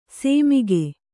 ♪ sēmige